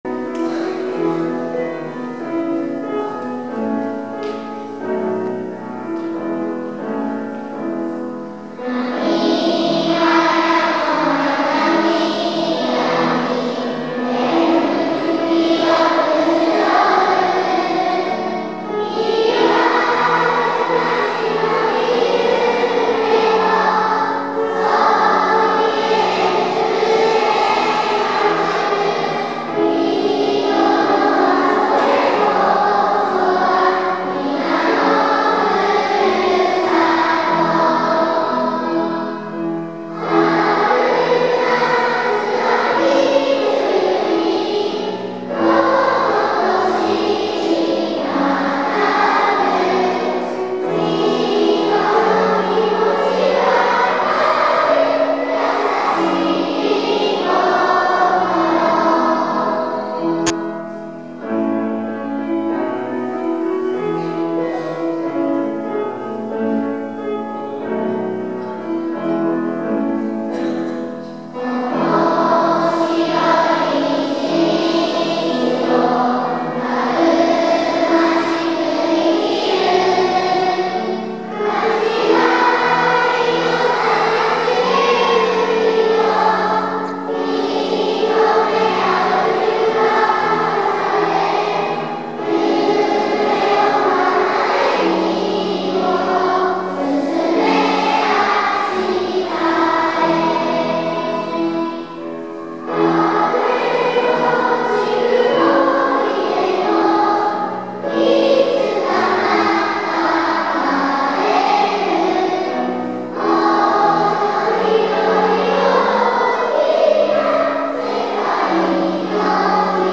小中合同で体育館で行いました。